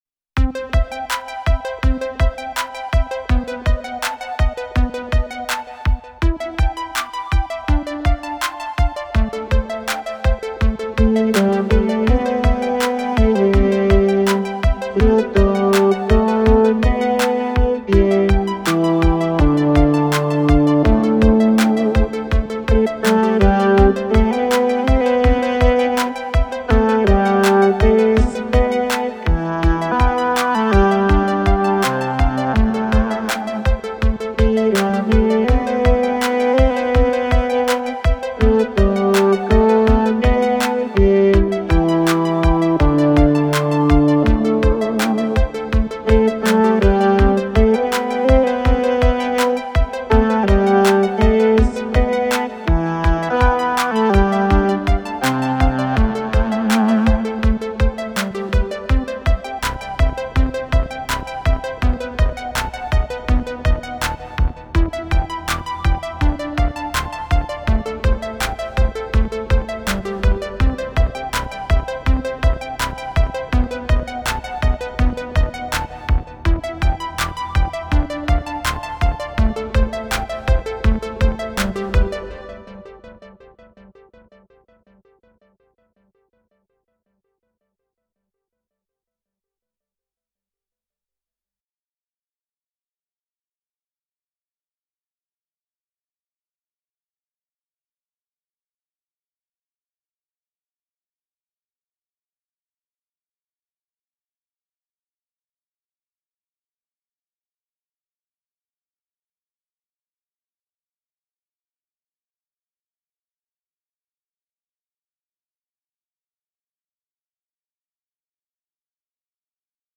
Recuerdo mis tardes de nervios en casa, porque el director y yo sabíamos que queríamos la obra con sonidos pop y de sintetizadores.
Una vez listos los arpegios de los acordes que quería, la melodía se cantó sola en mi cabeza: ¡Mírame!
El Espíritu Melodramático la cantaría con el efecto Vocoder en escena.